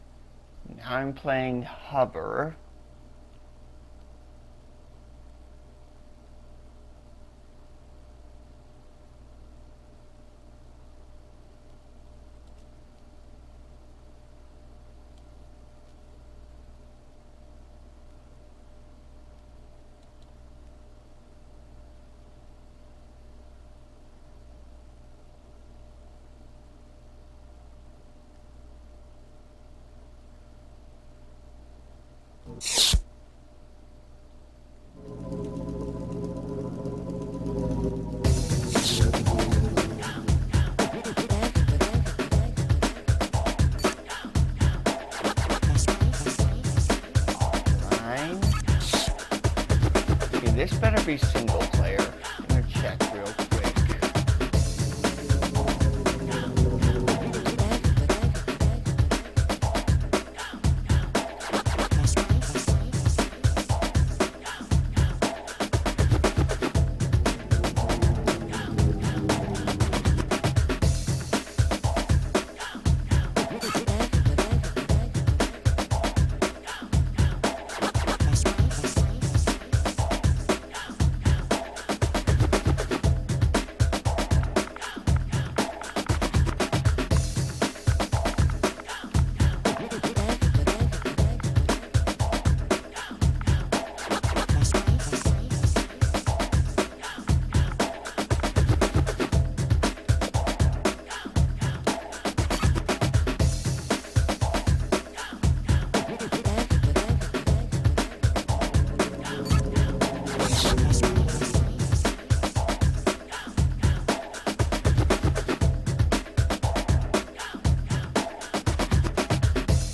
I play Hover with commentary